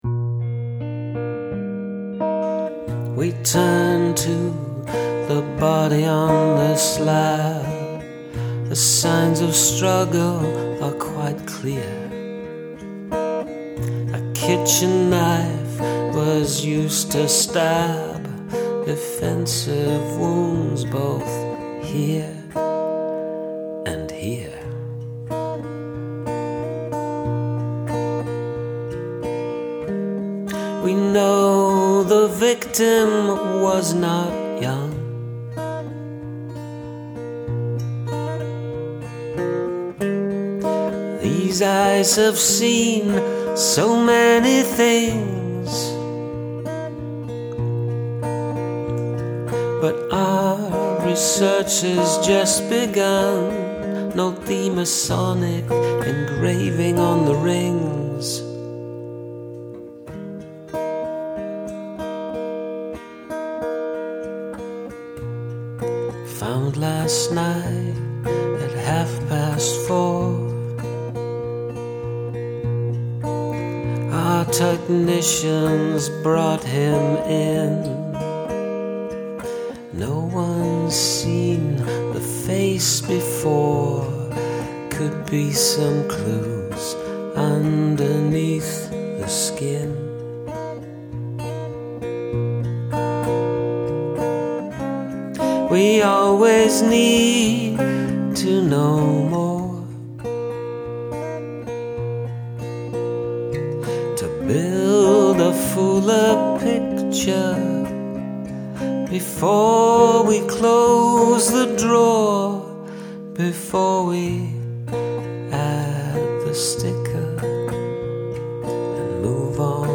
Dark
It's more whimsical than the dark I was expecting.